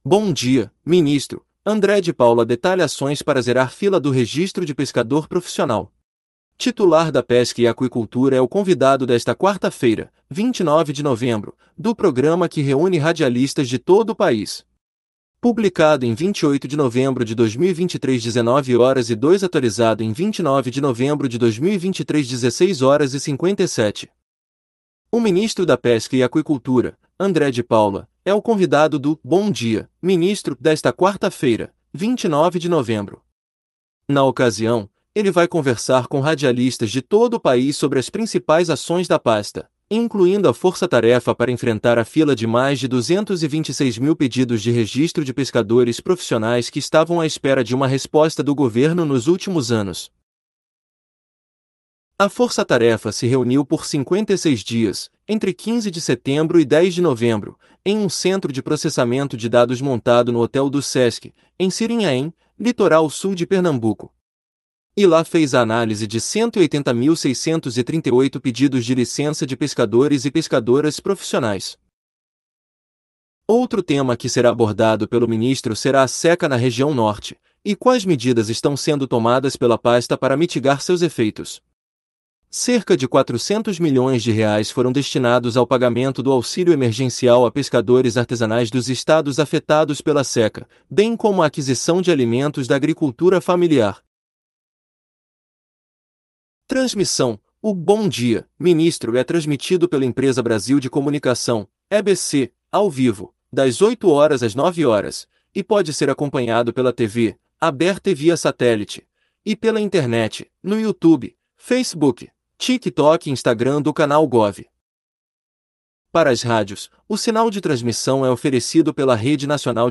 Titular da Pesca e Aquicultura é o convidado desta quarta-feira, 29 de novembro, do programa que reúne radialistas de todo o país